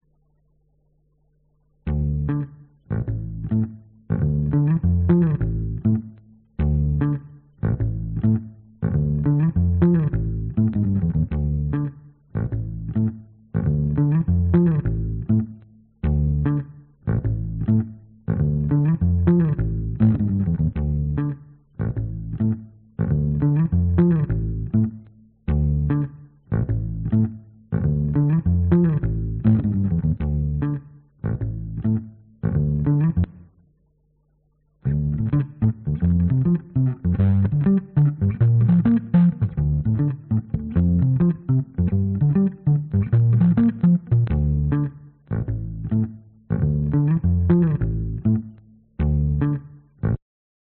Tag: 英国委员会 合作 跨文化 嘻哈 跨文化 猕猴桃 音乐 新西兰 人在你身边 雷鬼